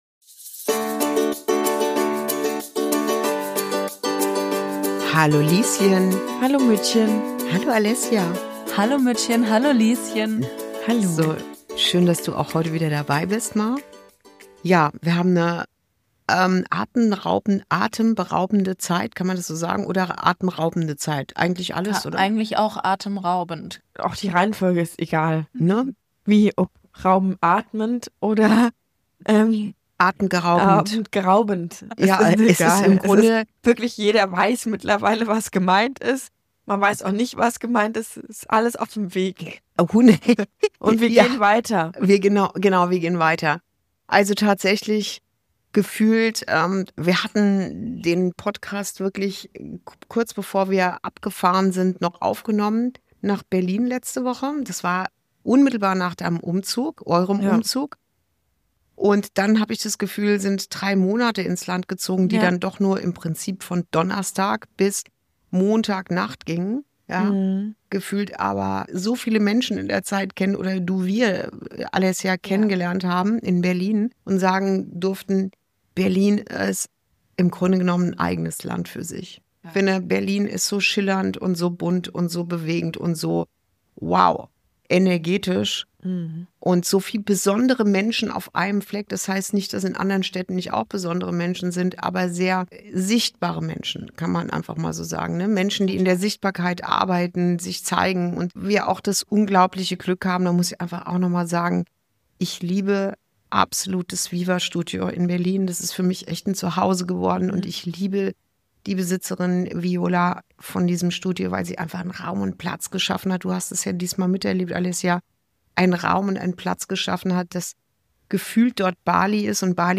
Folge 21: Atemraubend. Berlin. Und ein Ja fürs Leben. ~ Inside Out - Ein Gespräch zwischen Mutter und Tochter Podcast